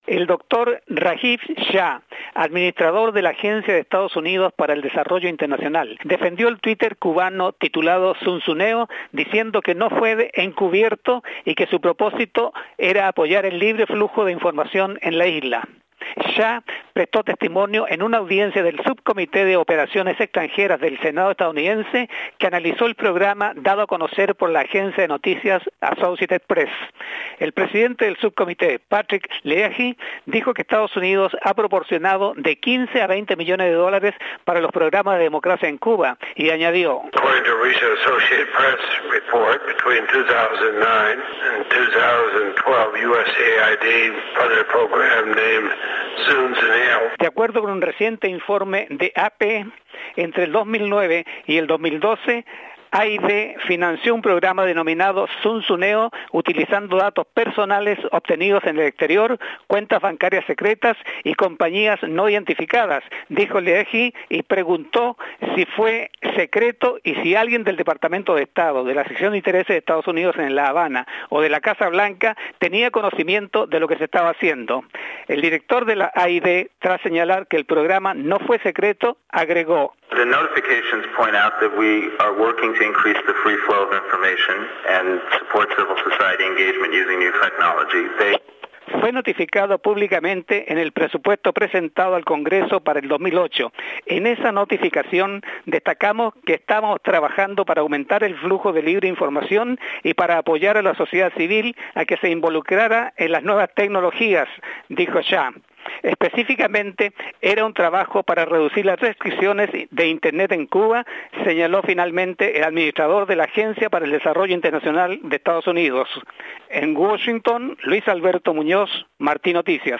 Reportaje
desde Washington DC